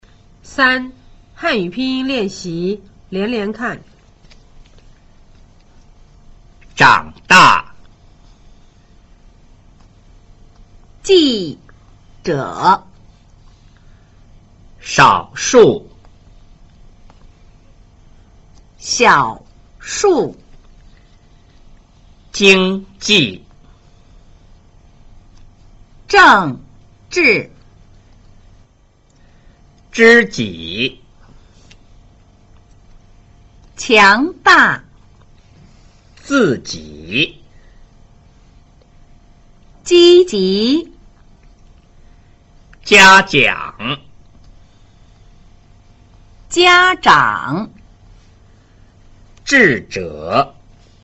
聲母 zh ch sh 翹舌音 和 j q x 舌面音的分辨
3. 漢語拼音練習連連看: